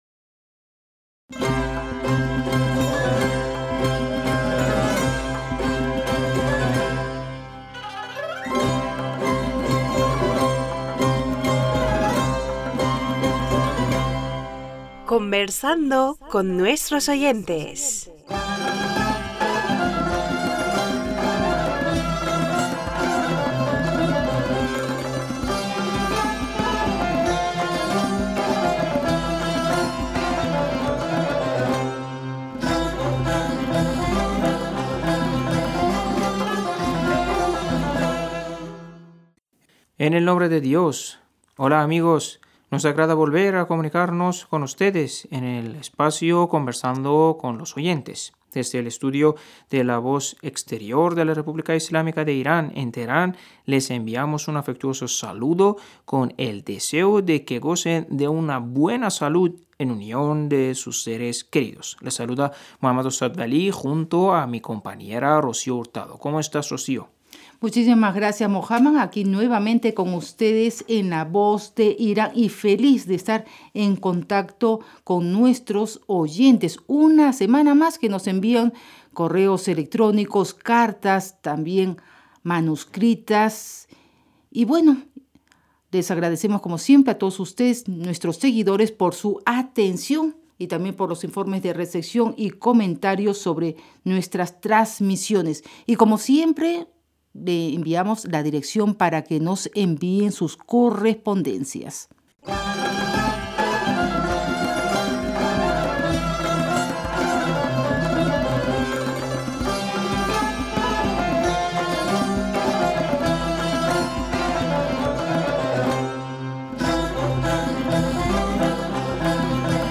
Pars Today-Las entrevistas, leer cartas y correos de los oyentes de la Voz Exterior de la R.I.I. en español.